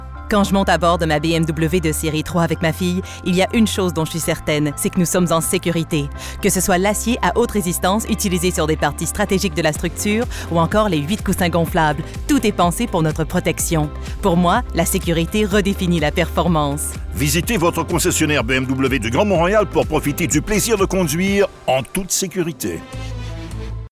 Publicité (BMW) - FR